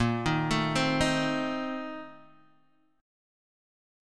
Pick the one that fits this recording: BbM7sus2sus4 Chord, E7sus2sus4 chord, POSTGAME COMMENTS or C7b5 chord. BbM7sus2sus4 Chord